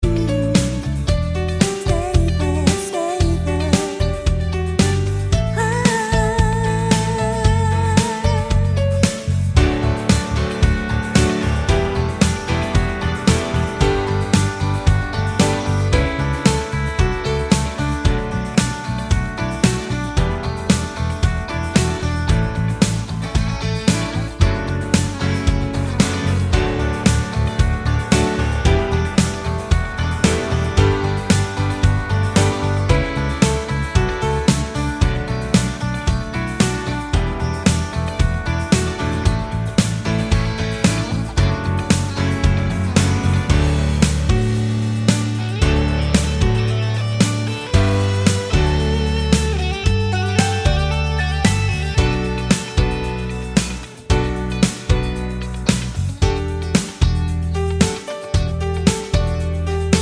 For Solo Male Pop (1980s) 4:19 Buy £1.50